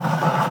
wood_m2.wav